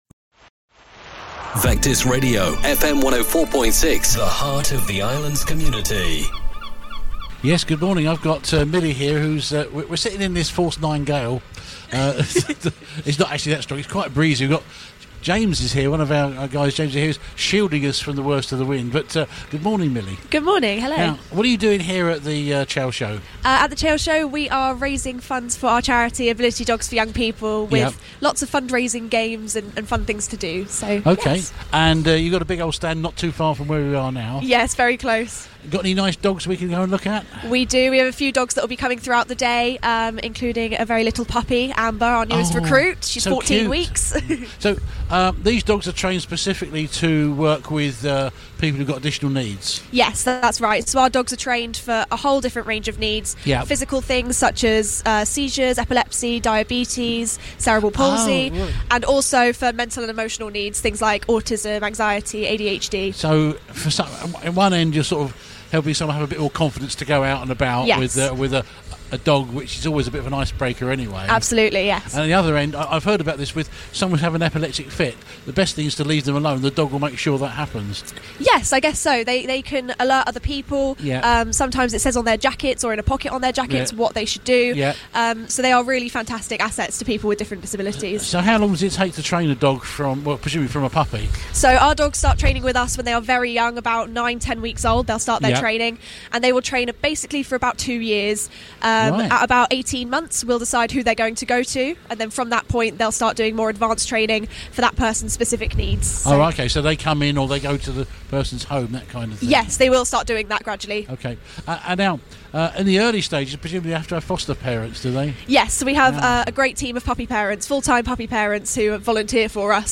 at The Chale Show 2025.